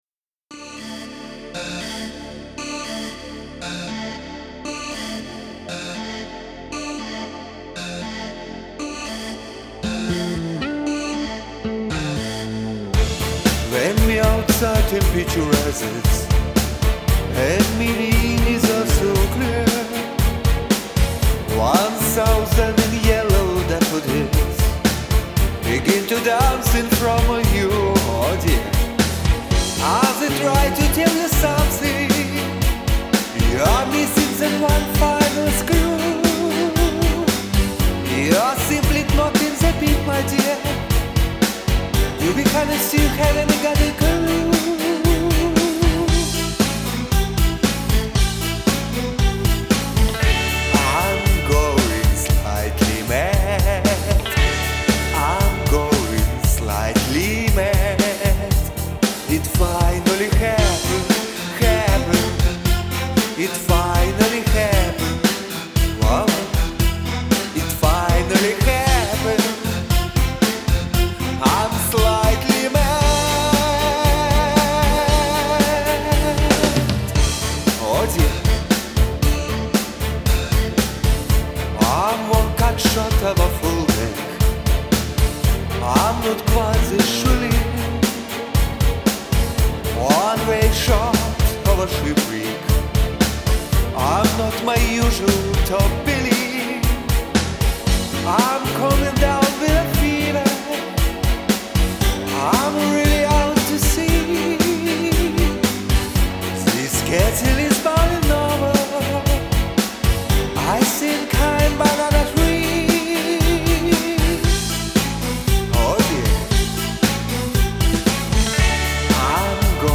ВСЕ "мелочи" пропел, всё интонационно выдержал!